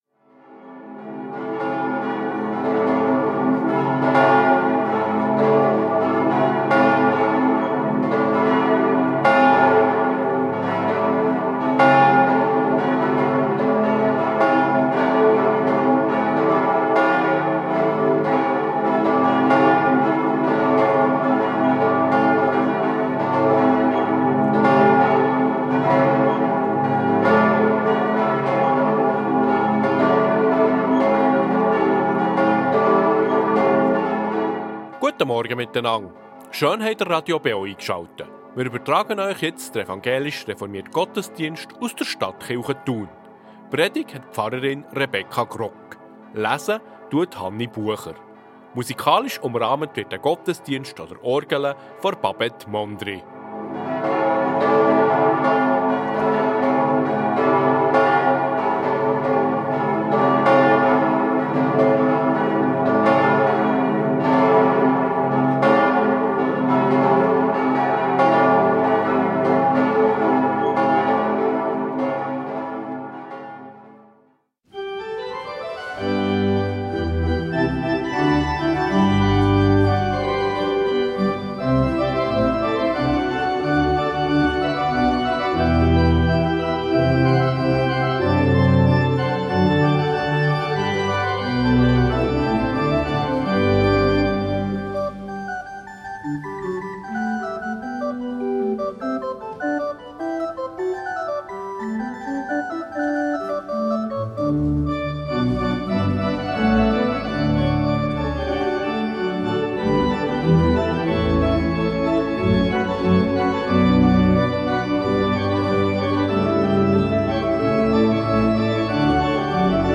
Reformierte Stadtkirche Thun ~ Gottesdienst auf Radio BeO Podcast